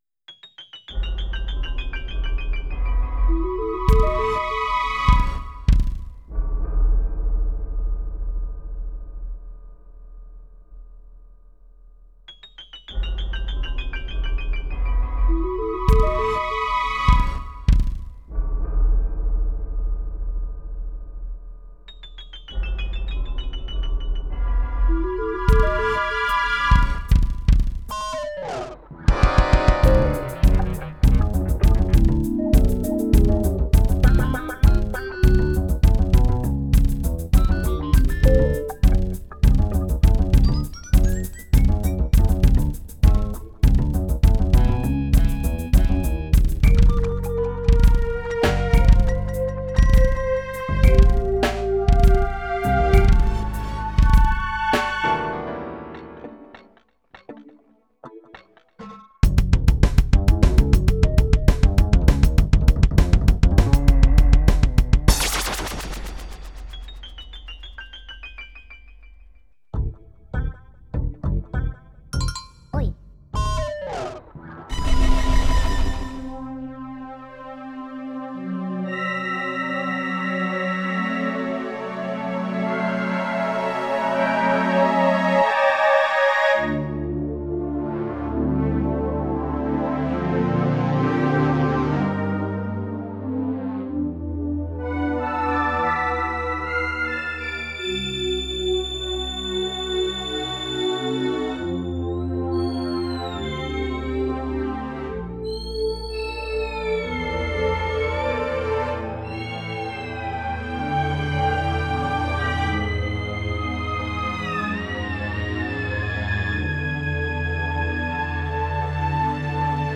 short inhale vocal performance